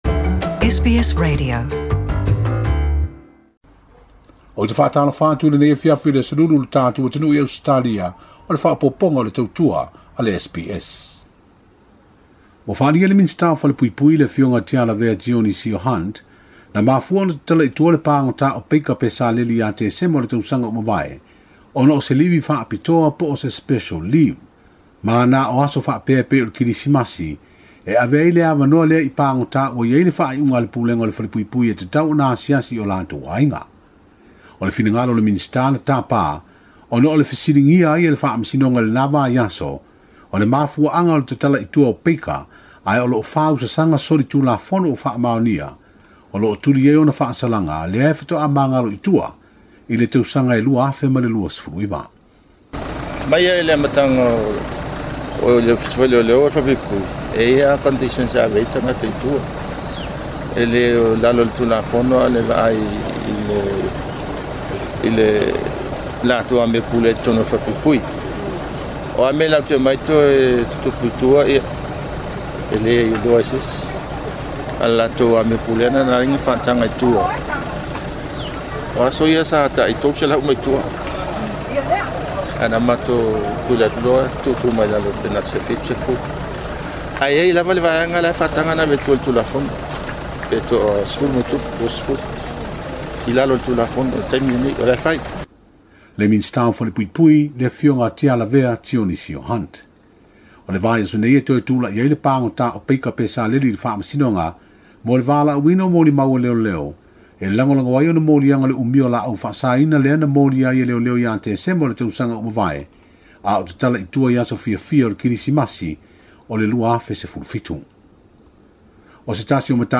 Talafou o Samoa mai Apia.